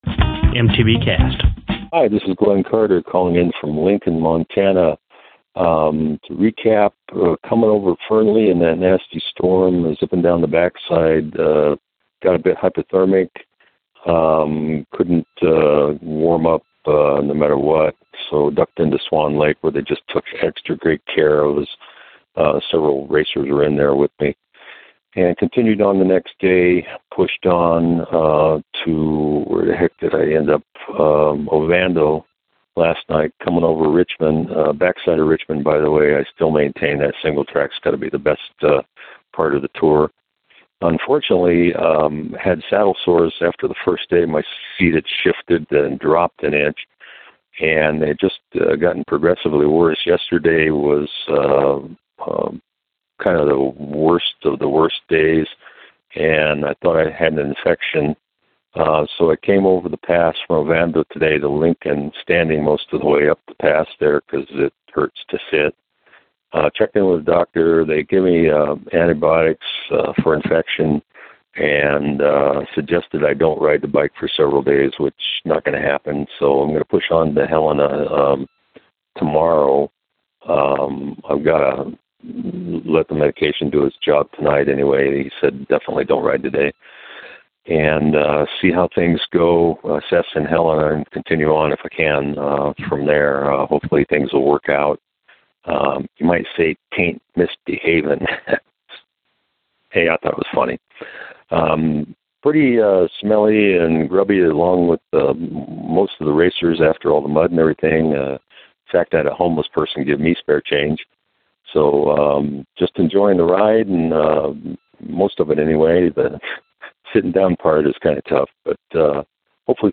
Posted in Calls , TD17 Tagged bikepacking , calls , cycling , MTBCast , TD17 , ultrasport permalink